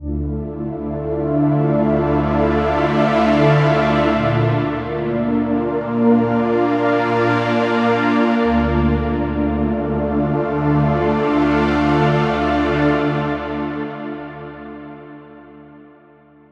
Because of this, even if it is digital synthesis, it sounds "warm".
All these demos are recorded directly from the ZynAddSubFX without audio processing with another program (well, exeption cutting/ OGG Vorbis compressing).